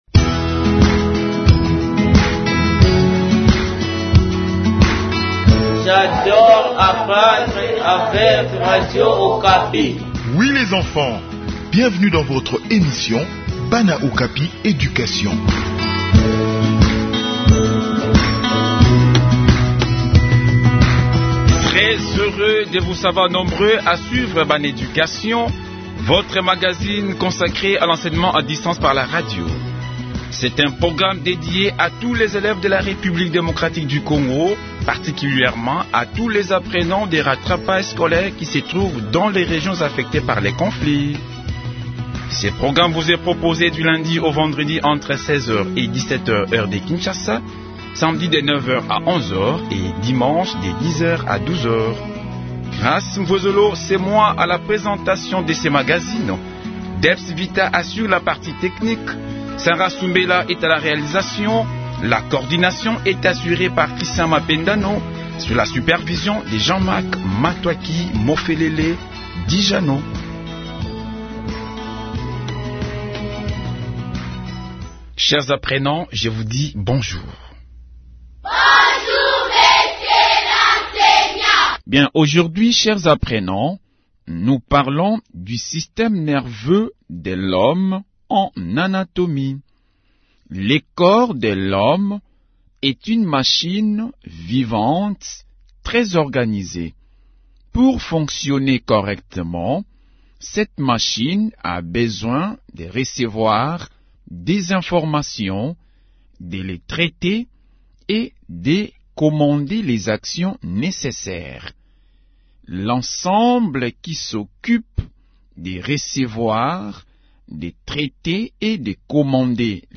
Enseignement à distance : leçon sur le système nerveux